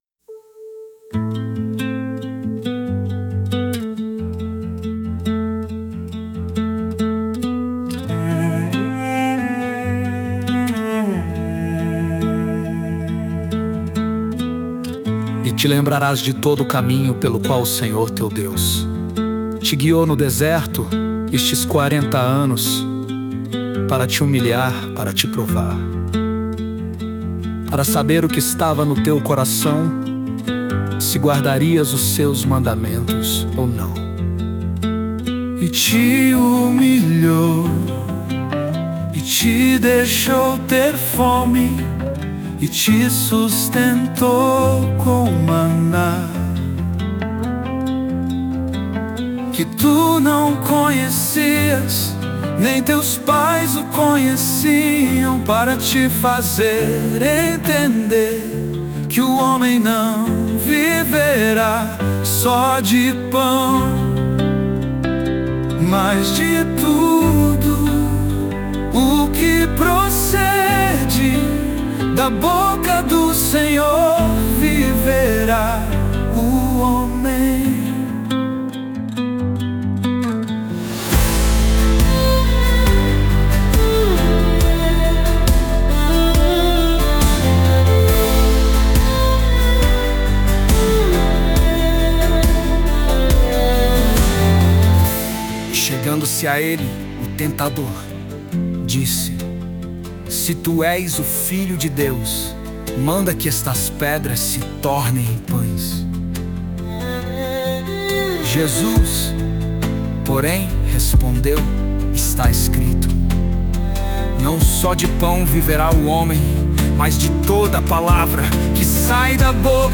Baixar Música Grátis: Louvores com Inteligência Artificial!